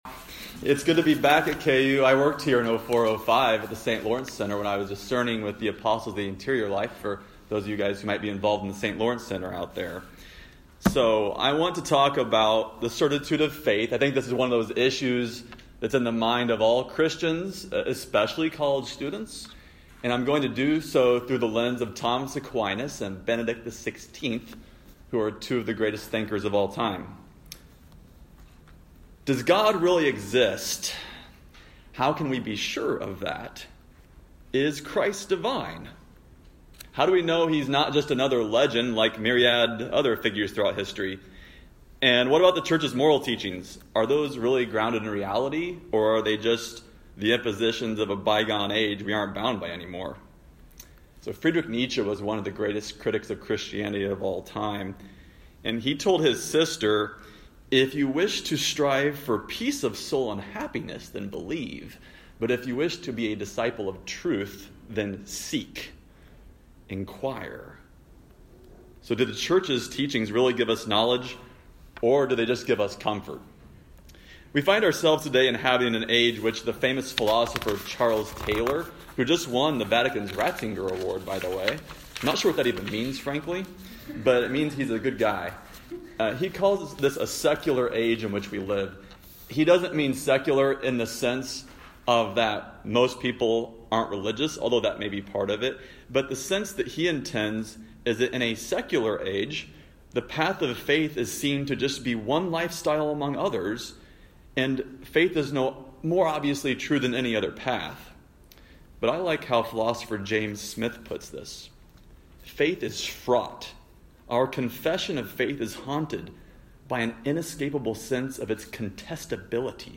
This lecture was given at the University of Kansas on November 14, 2019.